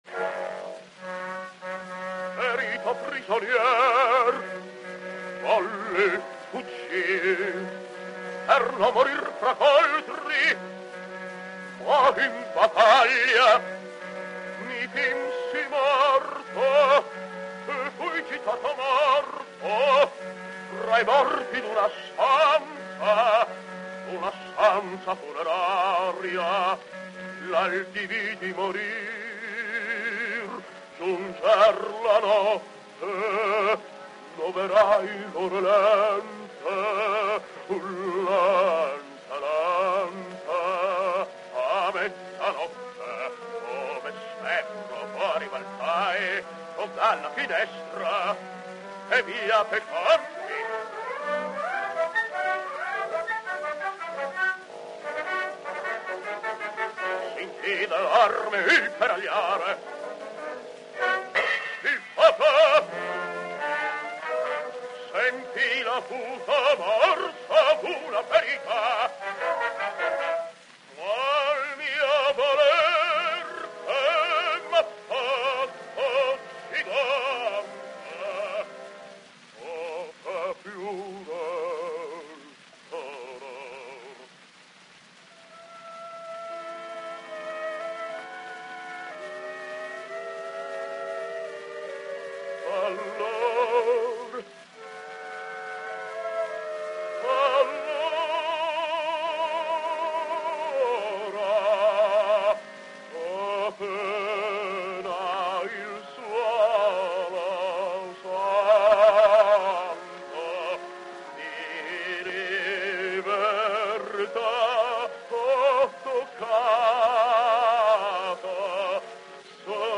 Carlo Galeffi [Bariton]